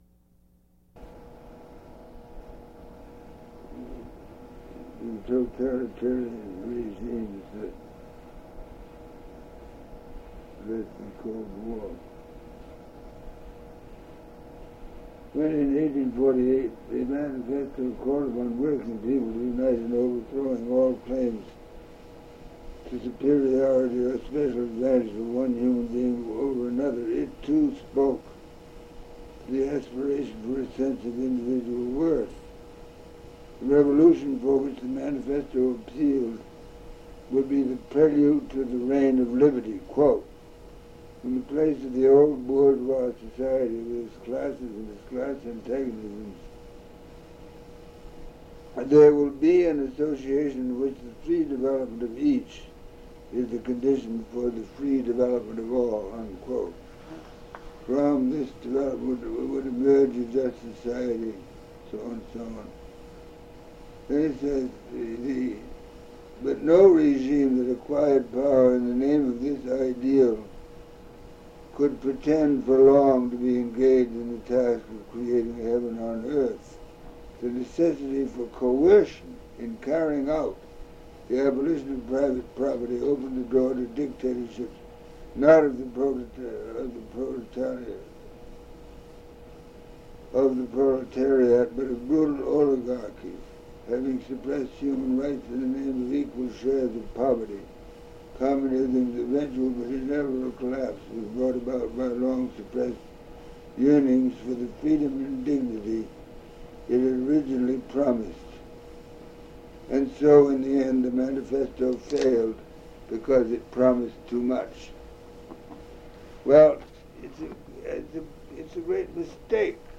Interview with Elliot Richardson /